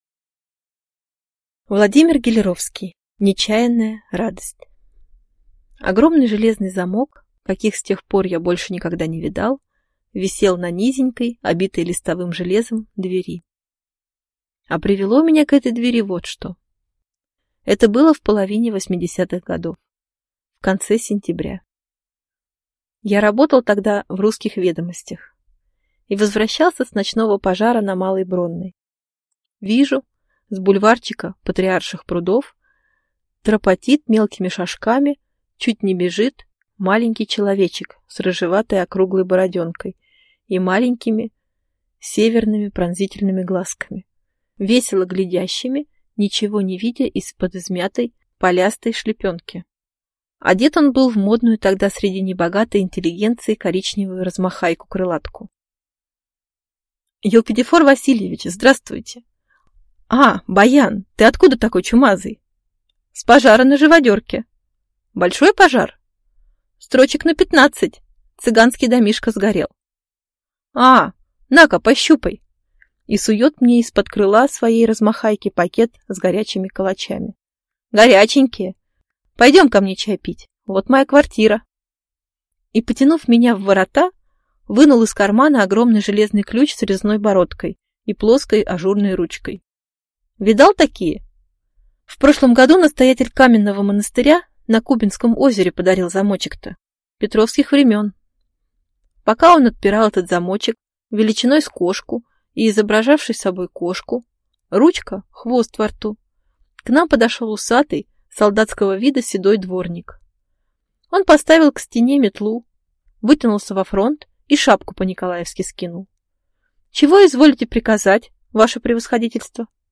Аудиокнига «Нечаянная радость» | Библиотека аудиокниг